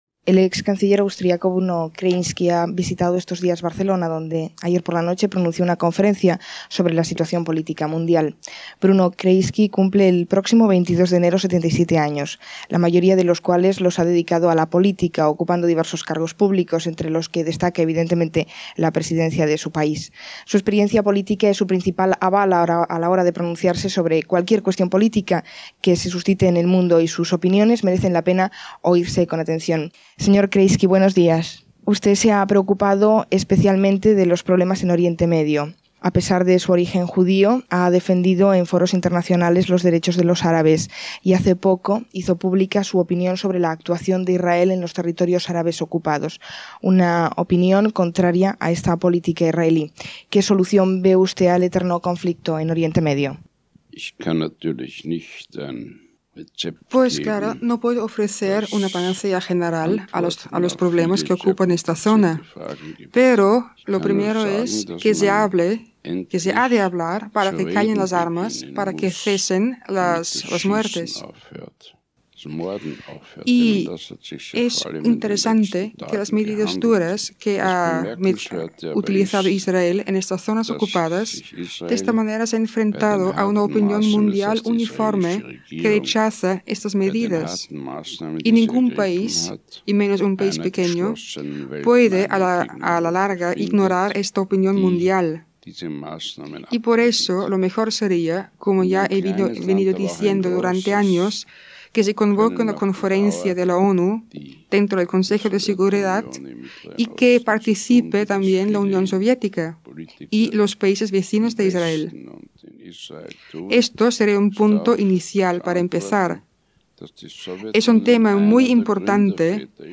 Entrevista al polític austríac Bruno Kreisky, que fou cancerller d'Àustria entre 1970 i 1983 i era vicepresident de la Inteinacional Socialista, el dia després que fes una conferència a l'Ajuntament de Barcelona, S'hi parla del conflicte entre Palestina i Israel, la política exterior dels Estats Units
Informatiu